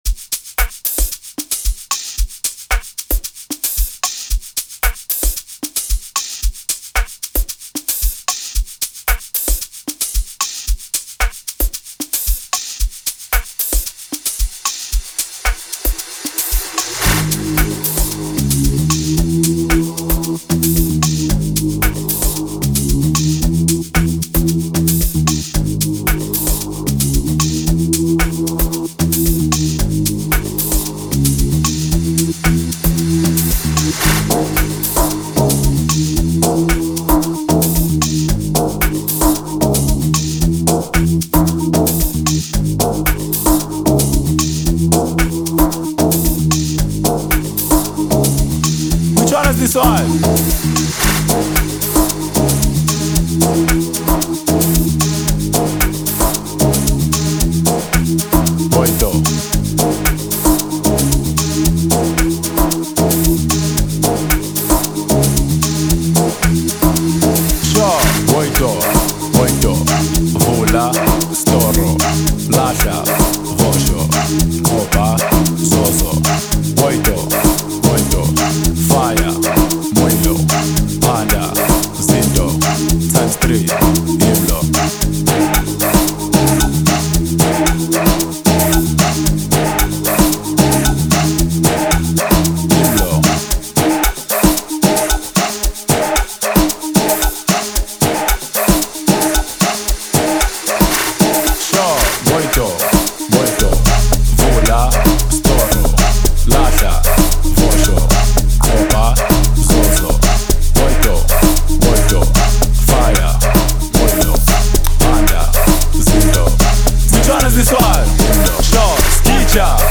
Amapiano, Gqom
This latest Amapiano music